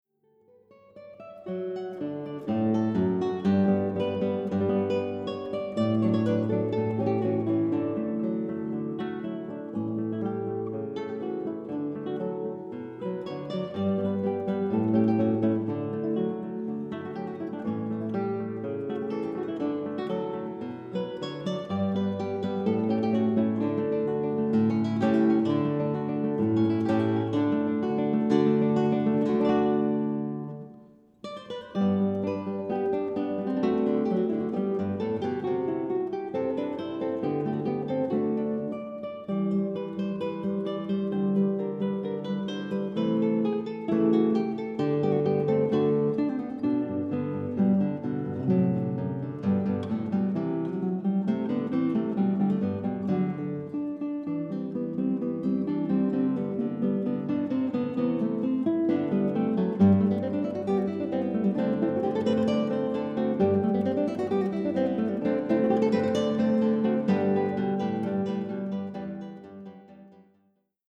Zwei Gitarren